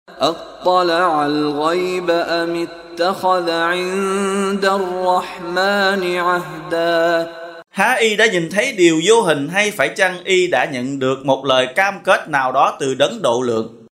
translation tác giả : Mushary bin Roshid Al-A’fasy
Đọc ý nghĩa nội dung chương Maryam bằng tiếng Việt có đính kèm giọng xướng đọc Qur’an